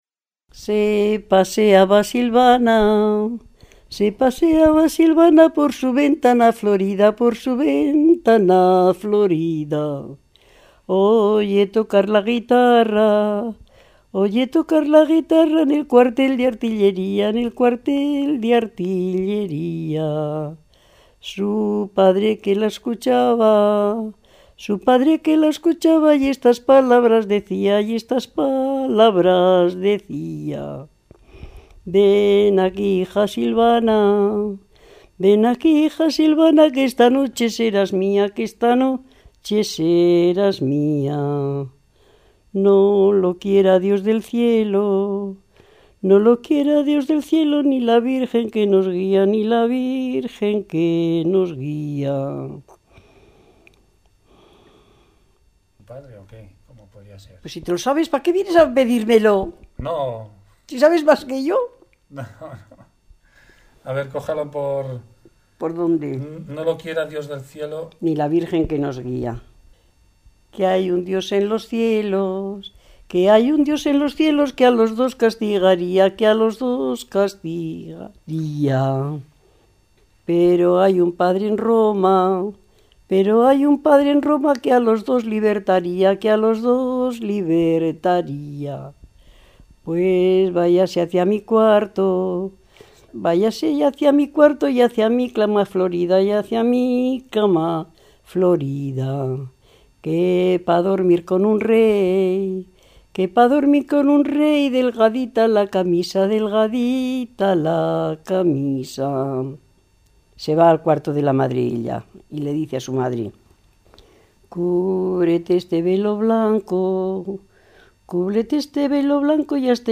Clasificación: Romancero
Lugar y fecha de recogida: Calahorra, 20 de julio de 2002